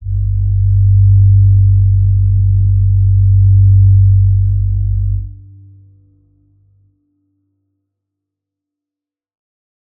G_Crystal-G2-pp.wav